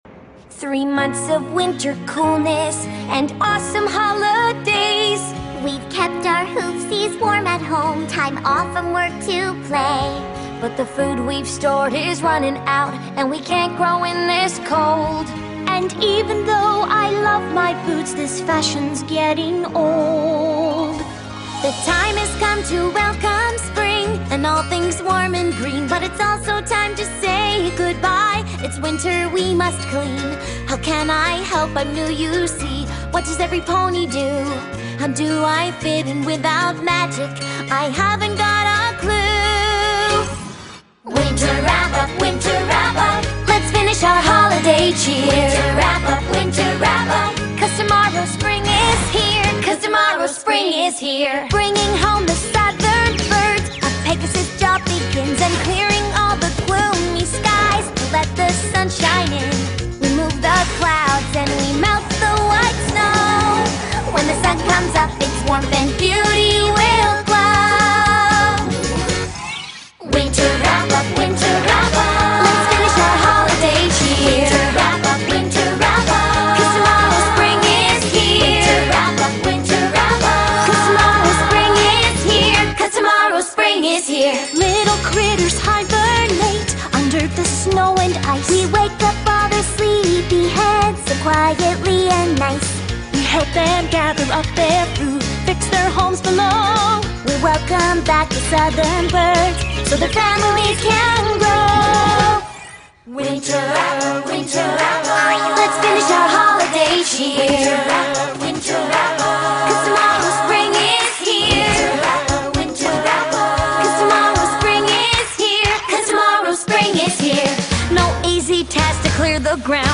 BPM48-116
Audio QualityCut From Video